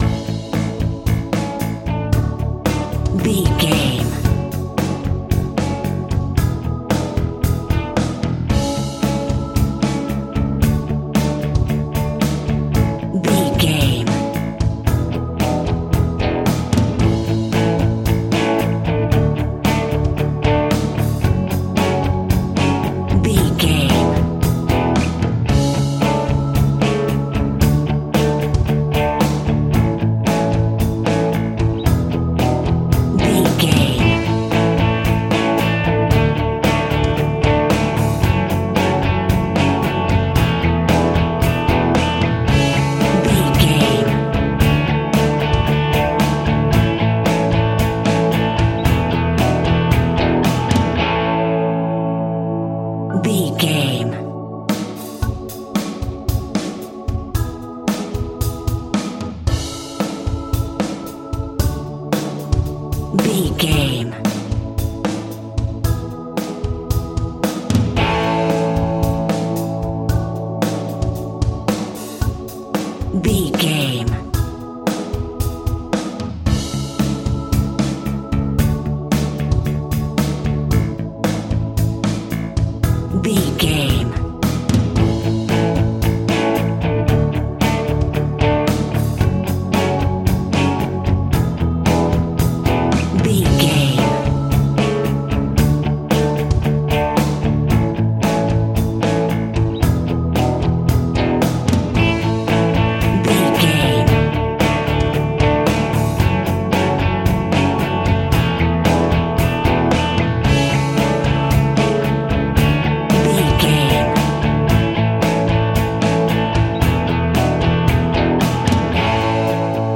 Ionian/Major
fun
energetic
uplifting
drums
bass guitar
electric guitar
synthesizers